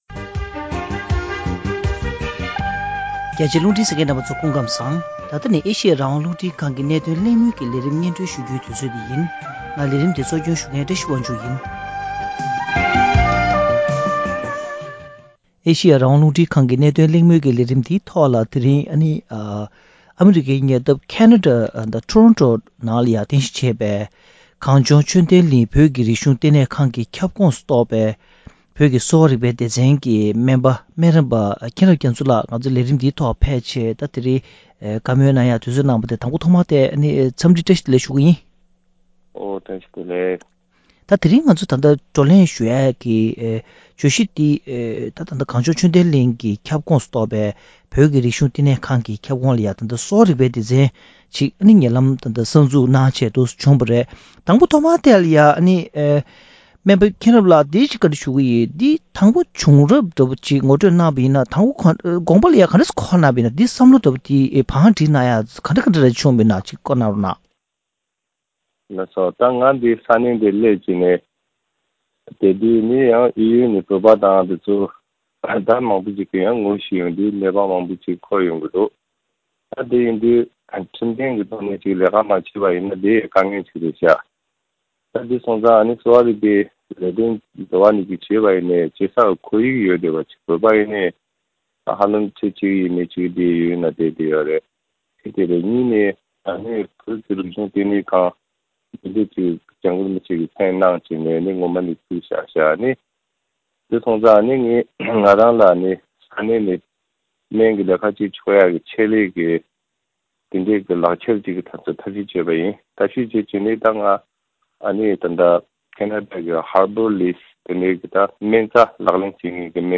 ནུབ་ཕྱོགས་སུ་བོད་ཀྱི་གསོ་རིག་སྨན་ལ་དོ་སྣང་ཇི་ཡོད་དང་མ་འོངས་མདུན་ལམ་ཐད་གླེང་མོལ།